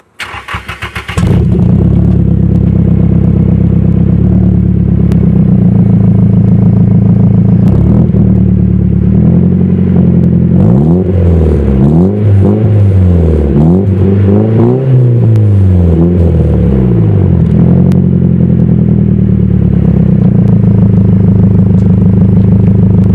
Hier mal den Sound vom Auspuff...
ps: schöner klang aber dreh ihn mal richtig hoch :yes: